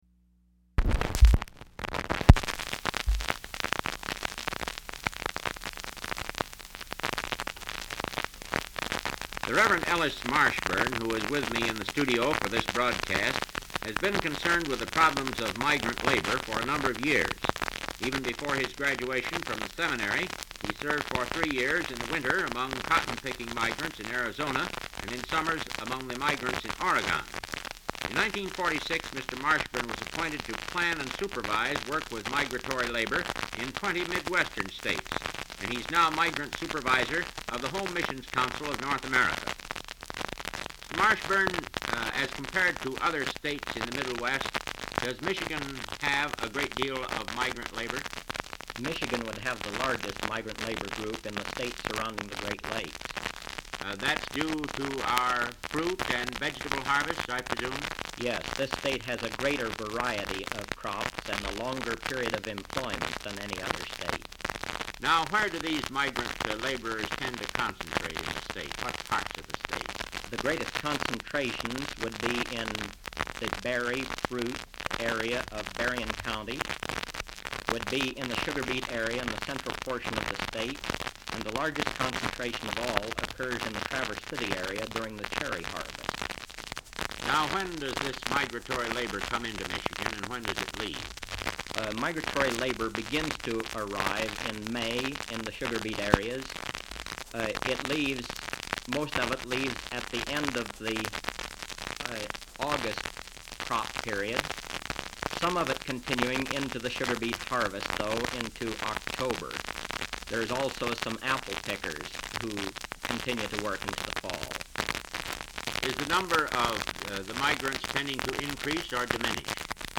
WKZO transcription disc collection